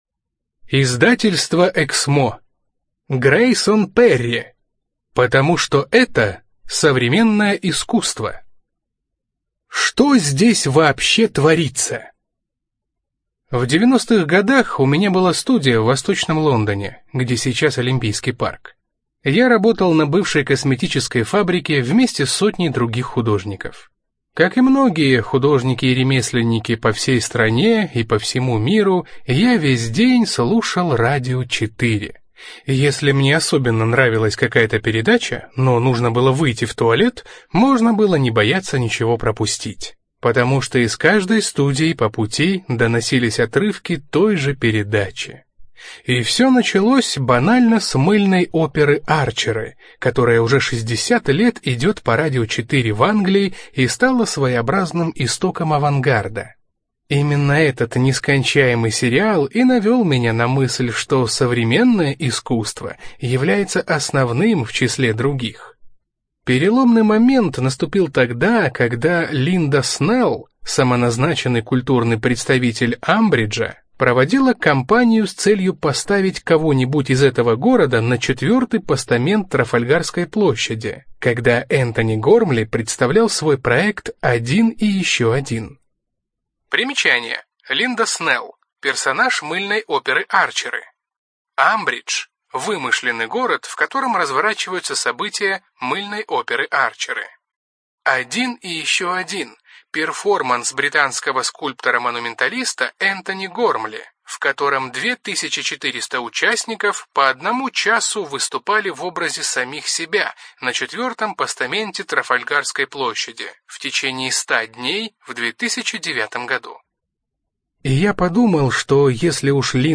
Студия звукозаписиЭКСМО